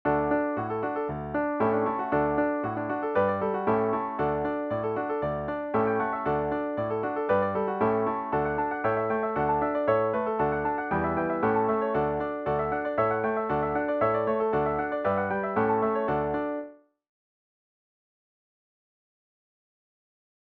DIGITAL SHEET MUSIC - PIANO ACCORDION SOLO